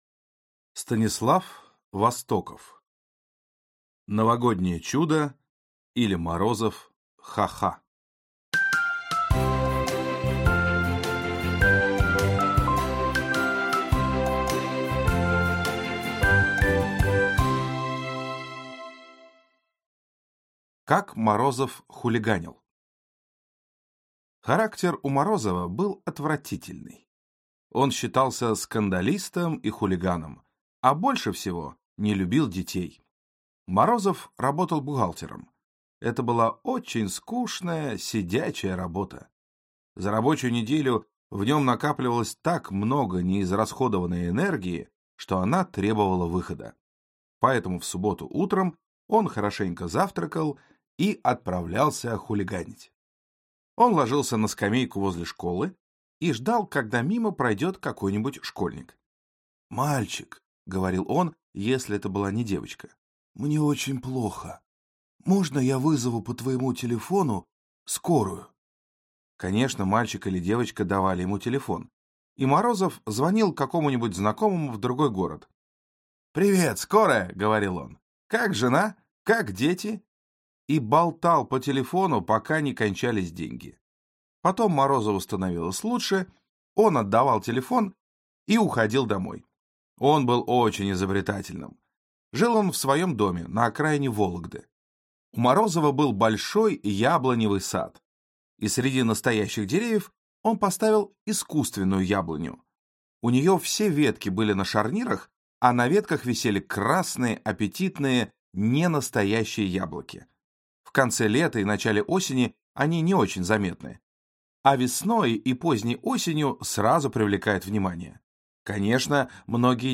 Аудиокнига Новогоднее чудо, или Морозов Ха. Ха.